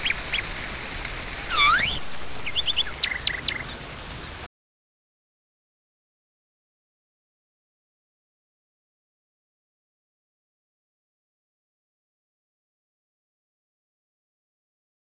The hunting call of the Bijeko-Lisea-Nas, recorded in the Afrikaanian Woodlands of Northern Corum.